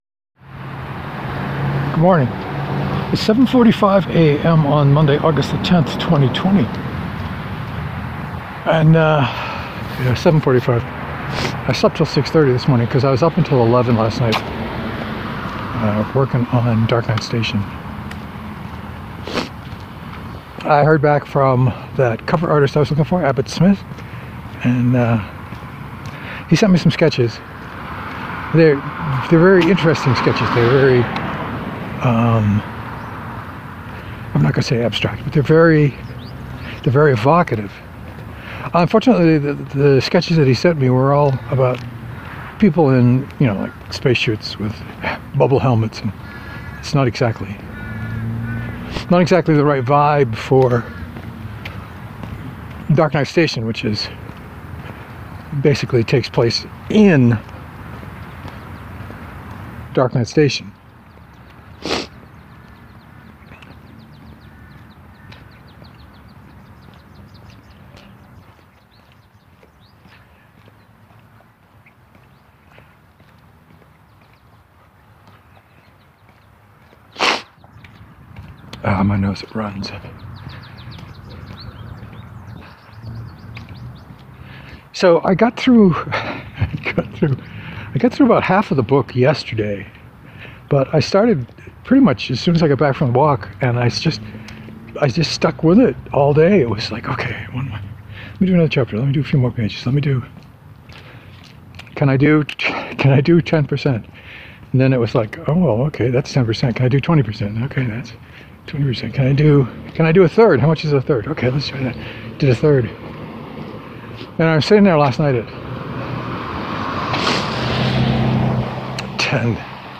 I talked fast and furiously today. Project status mostly.